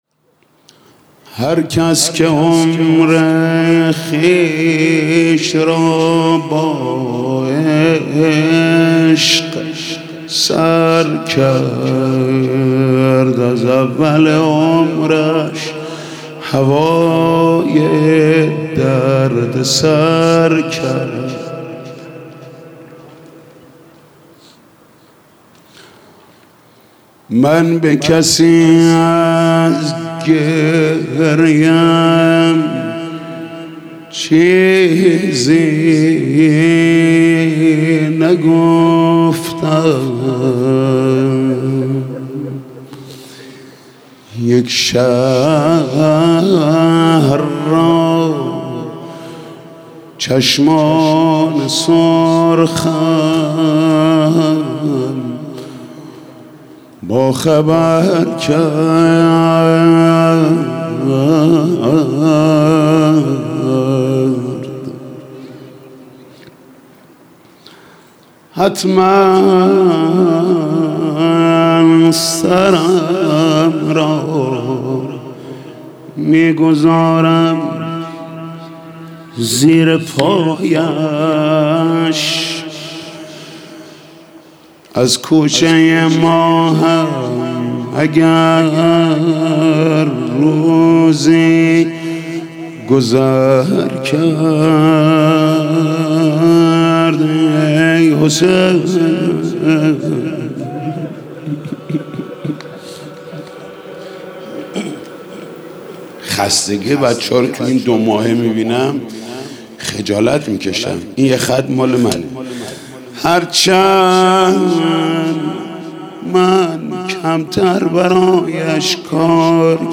گلچین فایل صوتی سخنرانی و مداحی شب هشتم محرم، اینجا قابل دریافت است.